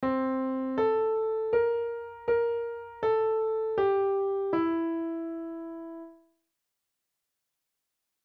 Piano Notes